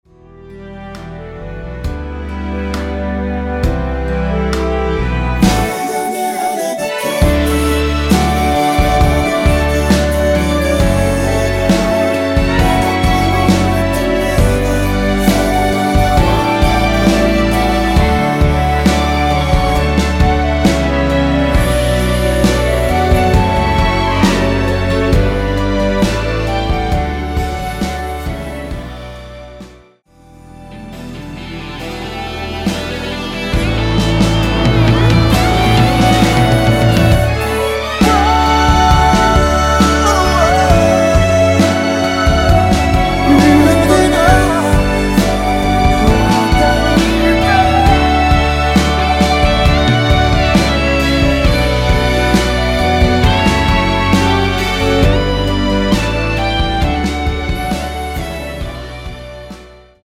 원키에서(-1)내린 멜로디와 코러스 포함된 MR입니다.(미리듣기 확인)
◈ 곡명 옆 (-1)은 반음 내림, (+1)은 반음 올림 입니다.
앞부분30초, 뒷부분30초씩 편집해서 올려 드리고 있습니다.